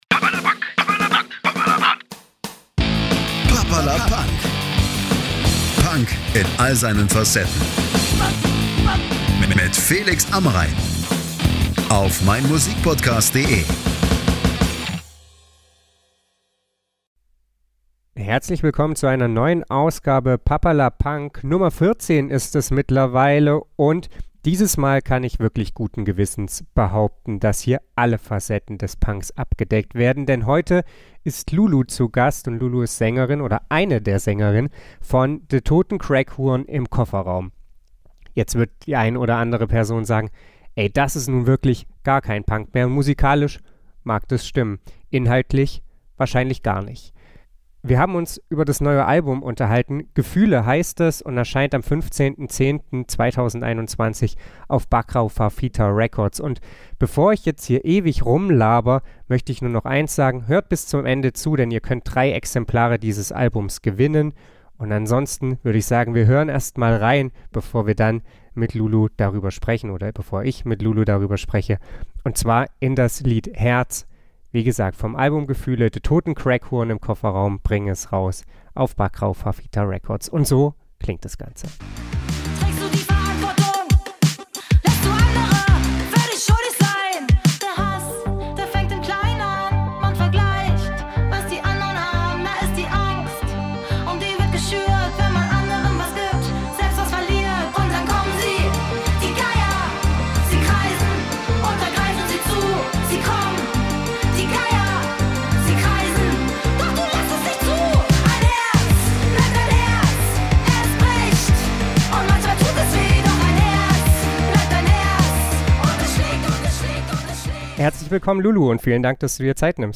Den Podcast haben wir am 11.10.2021 via Skype aufgenommen.